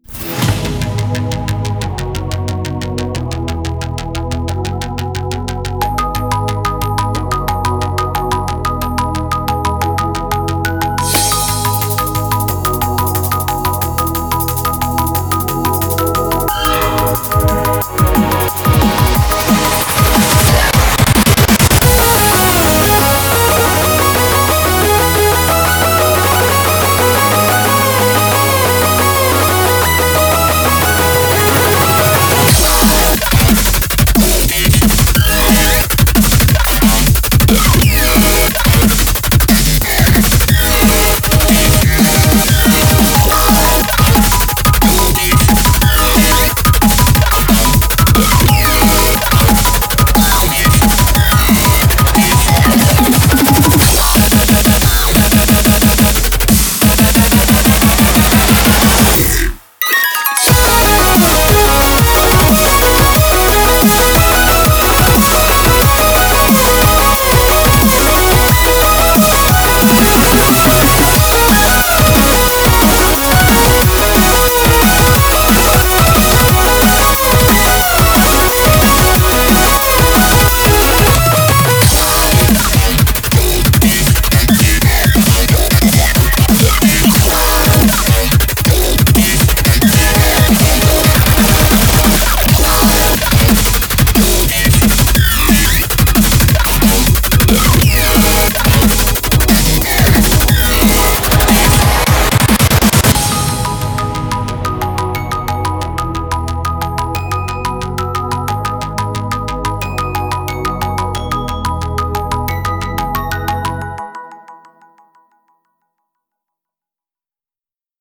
BPM90-180
If you hate wubs, you'll probably hate this song too.